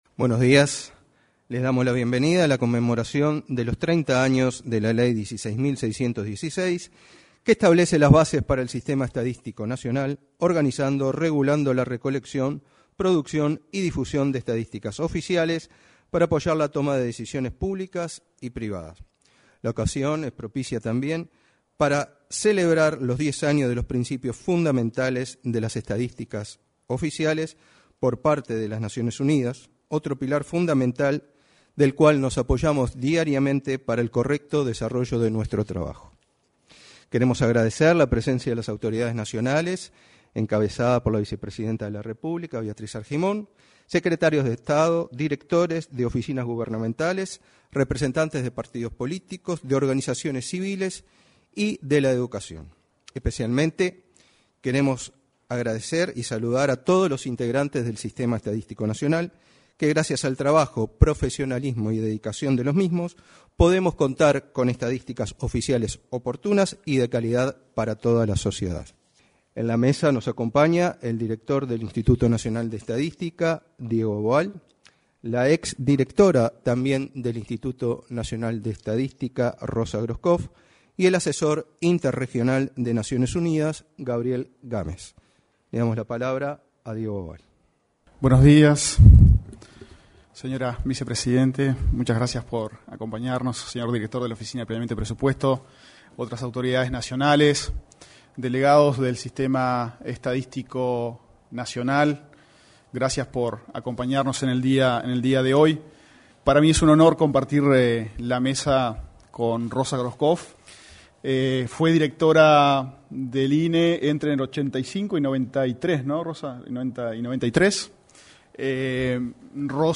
En el salón de actos de la Torre Ejecutiva, se desarrolló, este miércoles 27, una celebración por los 30 años del Sistema Estadístico Nacional y los